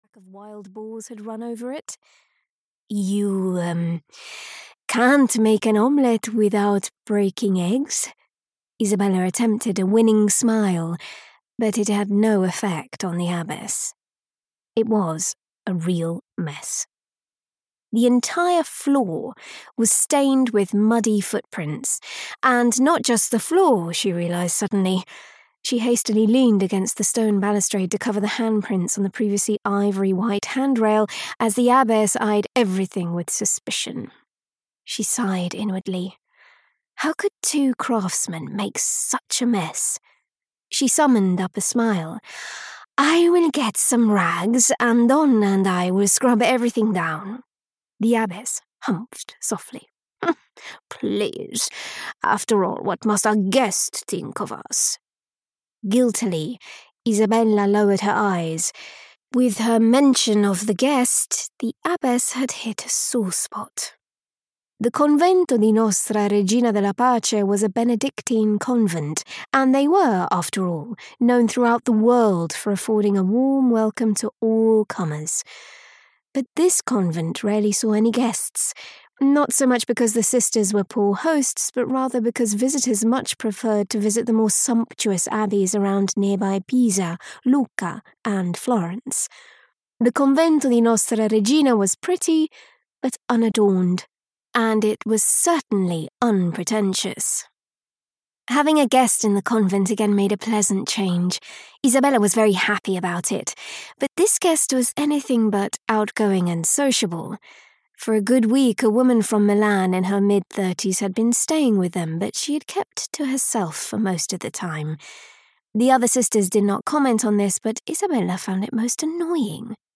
Death in the Cloisters (EN) audiokniha
Ukázka z knihy